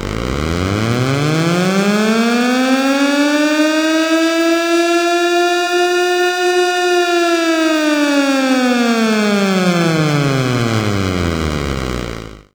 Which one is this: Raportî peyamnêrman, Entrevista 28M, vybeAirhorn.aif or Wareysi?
vybeAirhorn.aif